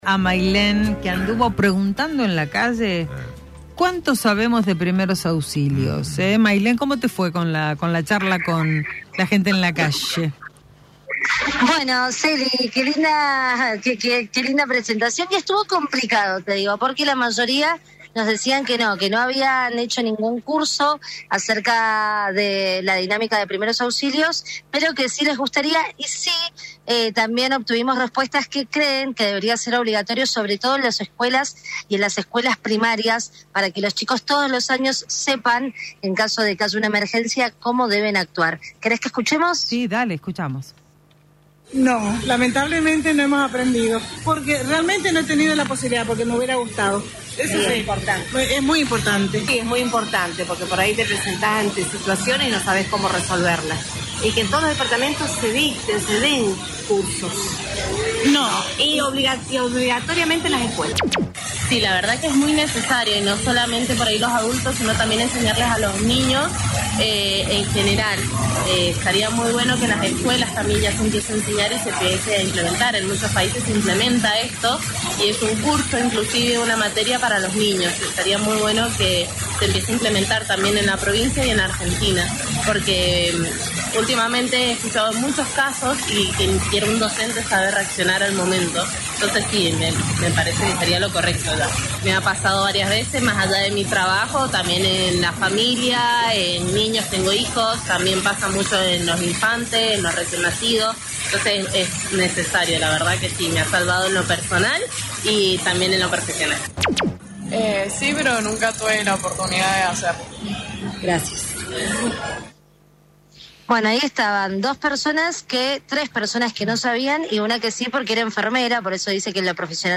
LVDiez - Radio de Cuyo - Móvil de LVDiez- Testimonios de mendocinos por primeros auxilios